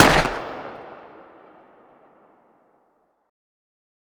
Decay/sound/weapons/arccw_ud/uzi/fire_dist.ogg at 5c1ce5c4e269838d7f7c7d5a2b98015d2ace9247
fire_dist.ogg